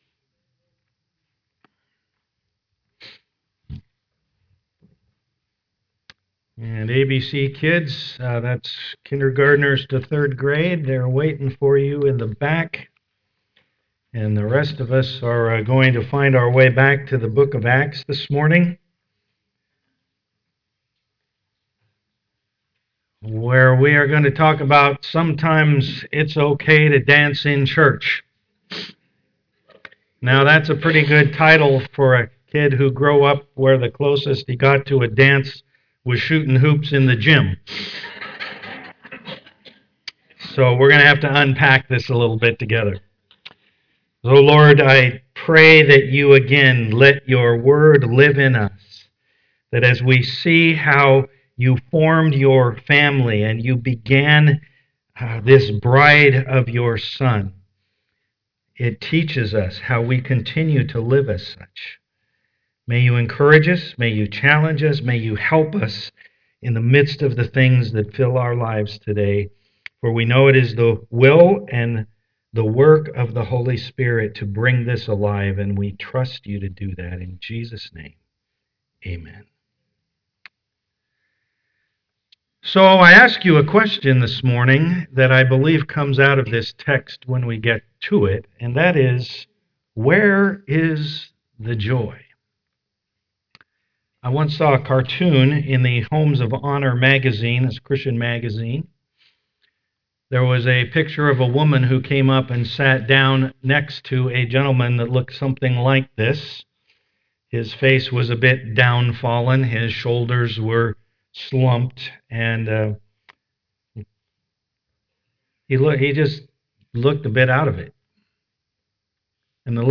Passage: Acts 3:1-11 Service Type: am worship Discussion questions are found on the "bulletin" download link below.